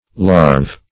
larve - definition of larve - synonyms, pronunciation, spelling from Free Dictionary Search Result for " larve" : The Collaborative International Dictionary of English v.0.48: Larve \Larve\ (l[aum]rv), n.; pl.